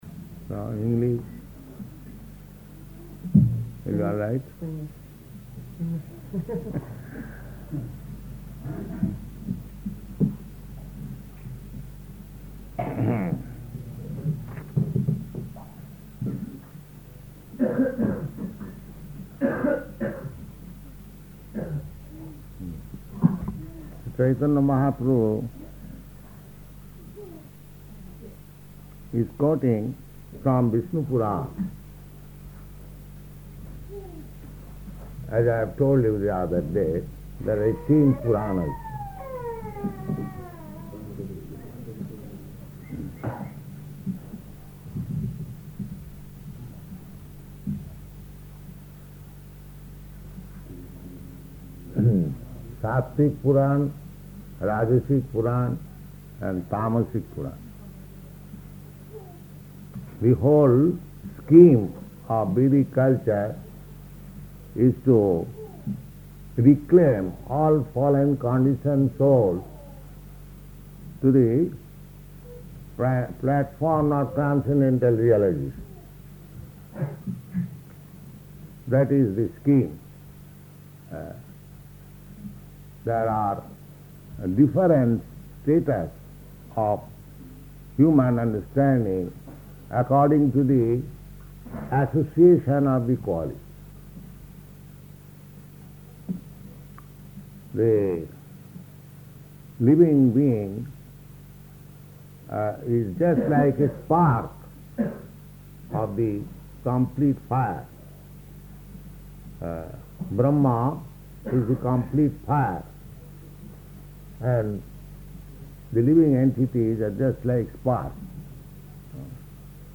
Śrī Caitanya-caritāmṛta, Madhya-līlā 6.154 --:-- --:-- Type: Caitanya-caritamrta Dated: February 16th 1971 Location: Gorakphur Audio file: 710216CC-GORAKPHUR.mp3 Prabhupāda: So English is all right?